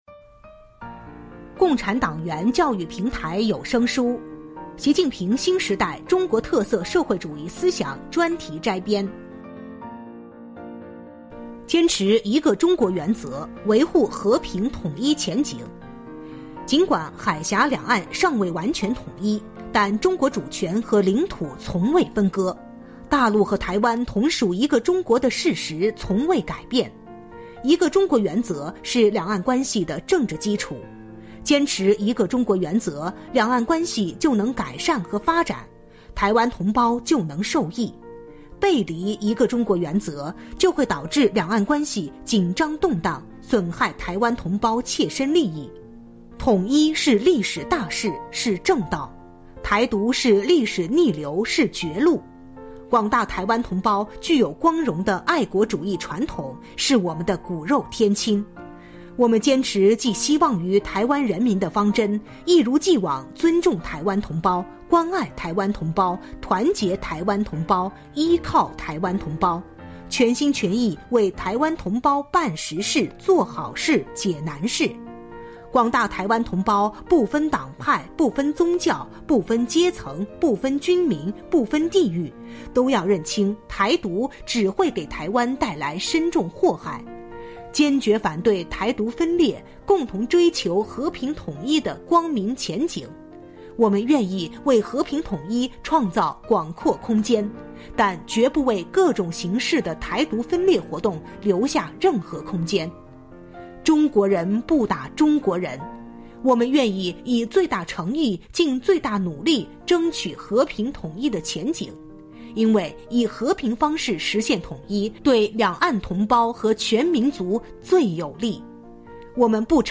聊城机关党建 - 《习近平新时代中国特色社会主义思想专题摘编》 - 主题教育有声书 《习近平新时代中国特色社会主义思想专题摘编》（73）